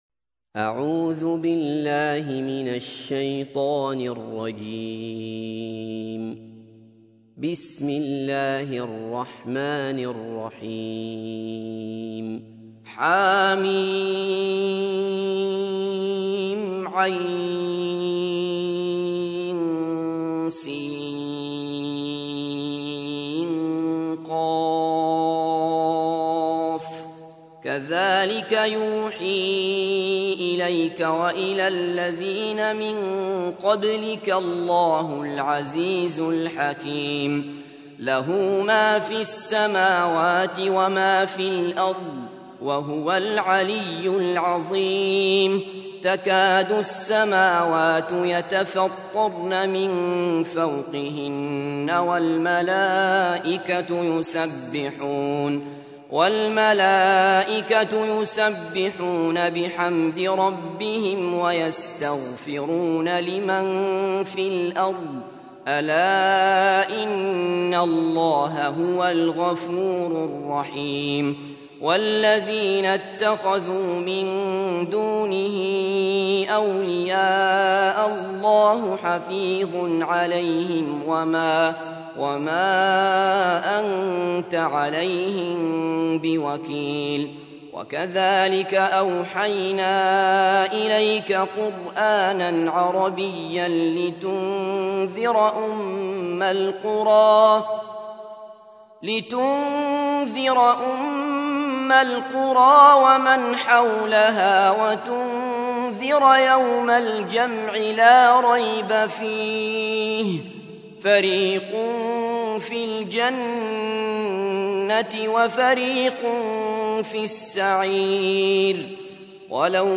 تحميل : 42. سورة الشورى / القارئ عبد الله بصفر / القرآن الكريم / موقع يا حسين